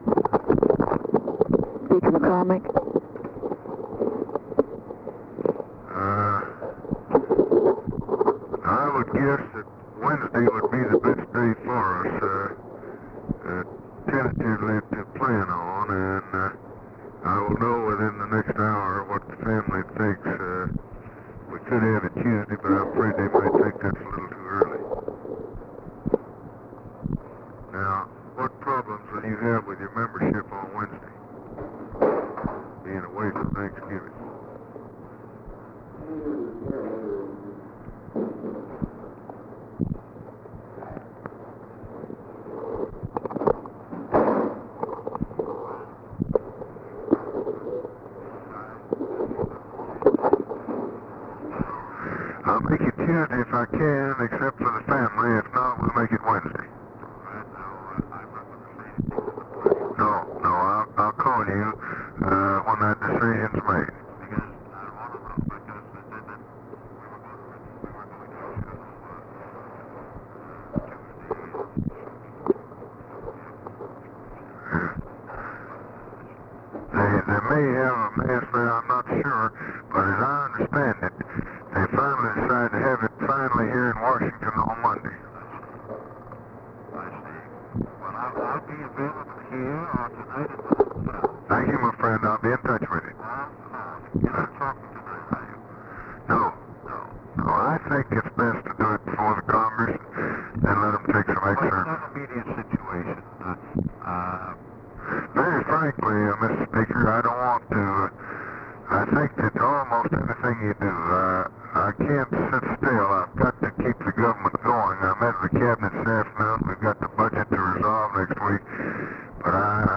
Conversation with JOHN MCCORMACK, November 23, 1963
Secret White House Tapes